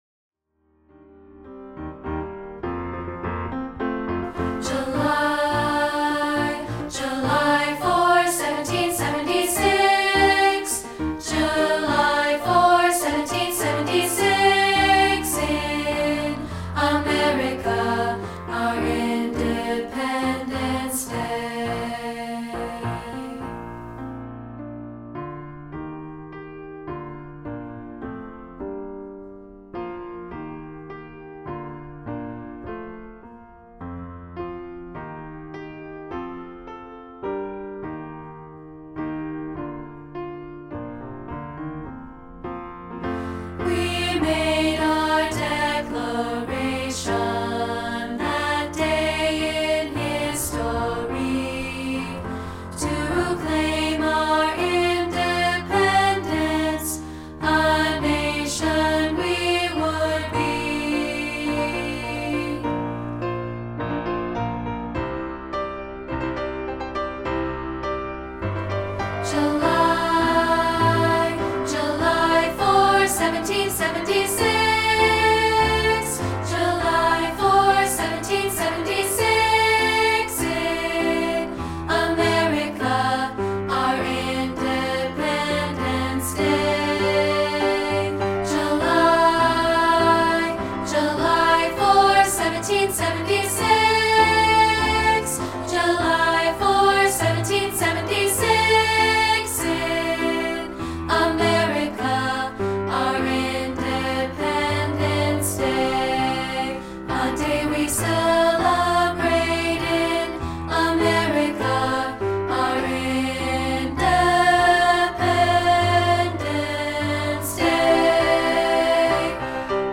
Here's a rehearsal track of part 2, isolated
patriotic song